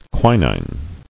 [qui·nine]